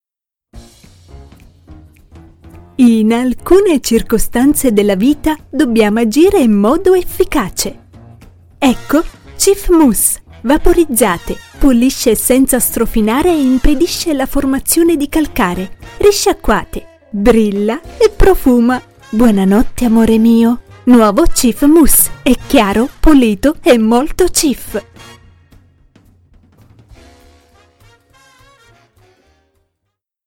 IT RF MOV 01 TV shows and Movies Female Italian